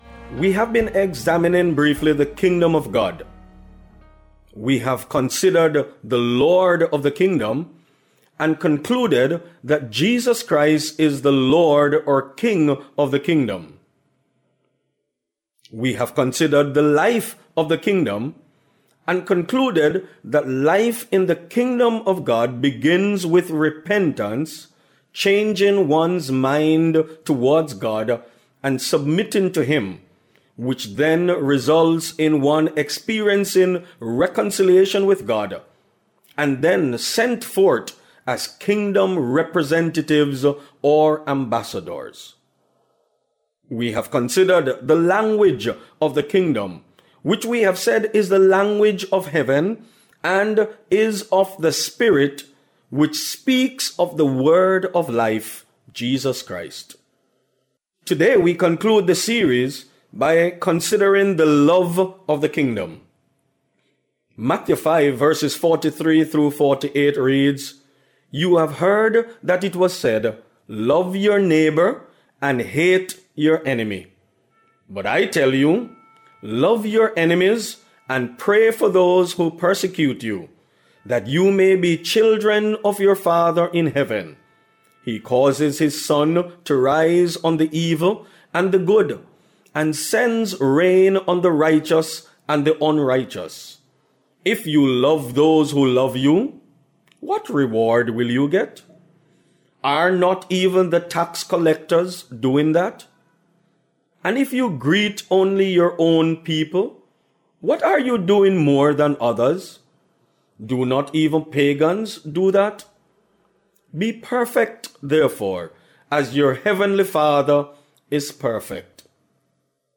Grace Hour Broadcast